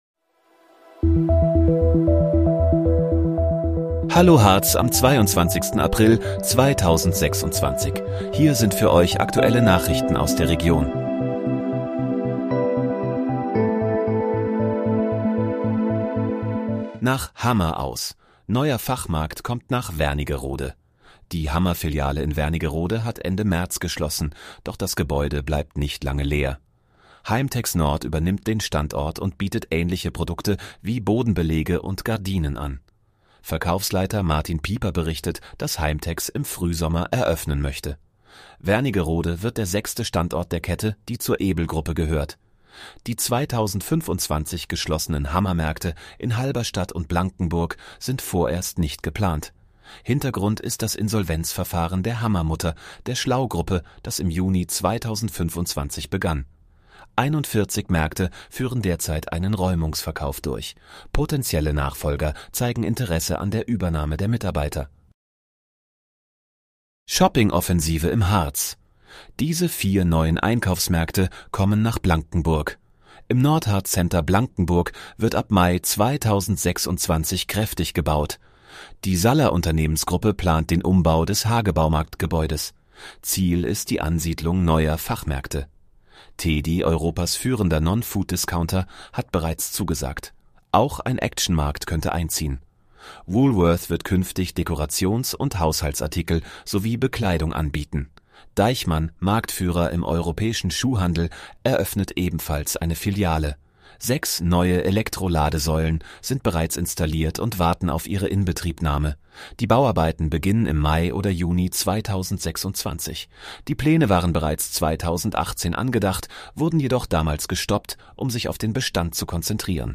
Hallo, Harz: Aktuelle Nachrichten vom 22.04.2026, erstellt mit KI-Unterstützung